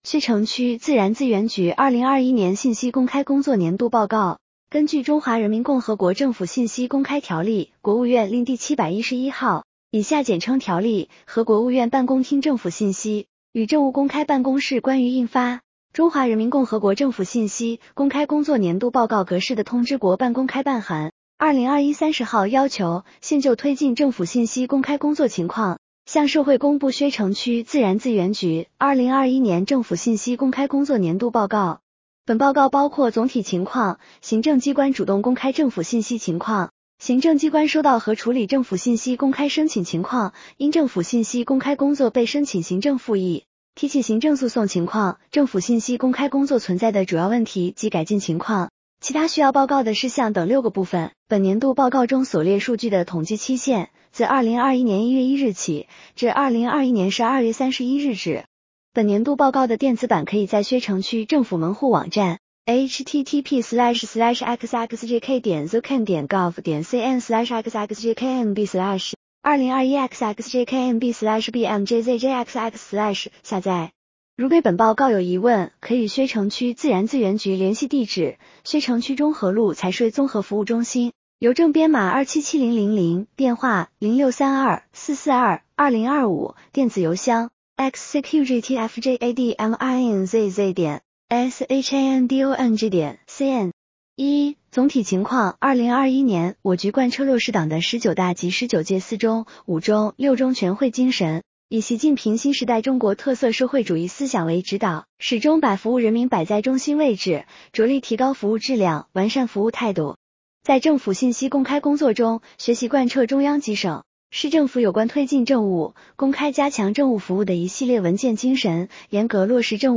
点击接收年报语音朗读 薛城区自然资源局2021年信息公开工作年度报告